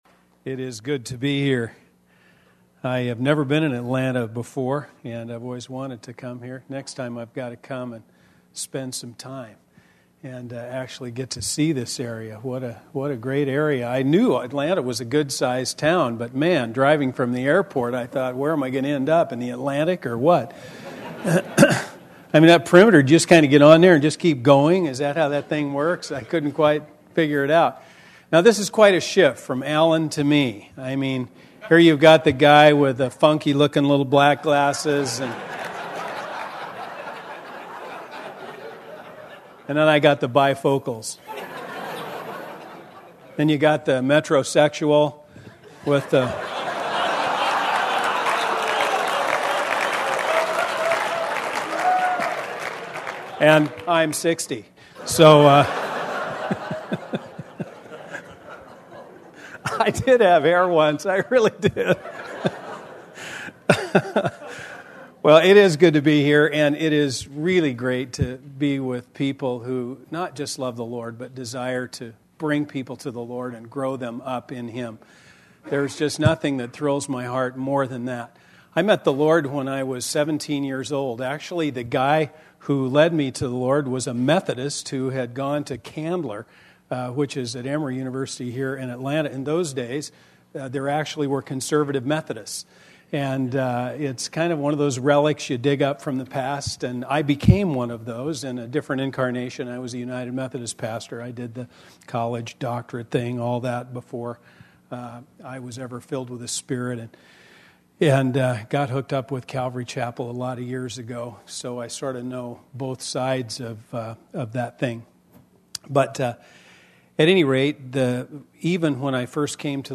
2005 DSPC Conference: Pastors & Leaders Date